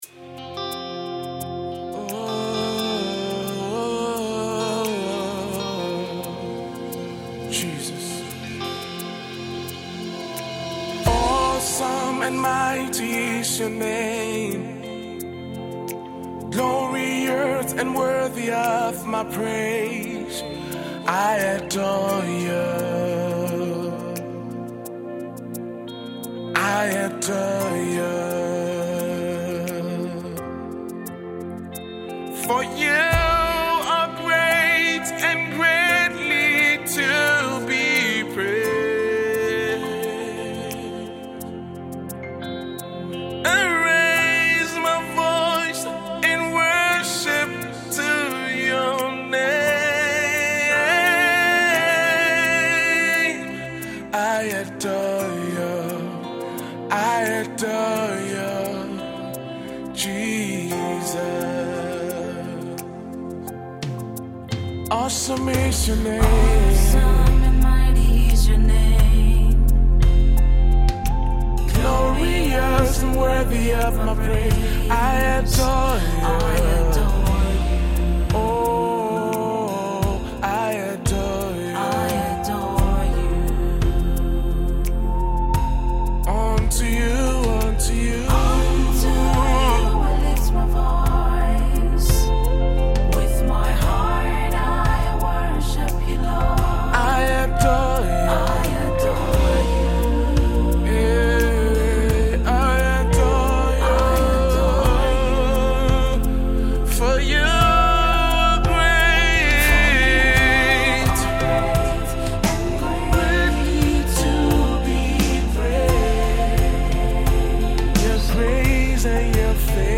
contemporary gospel musician
a song of worship and adoration to God.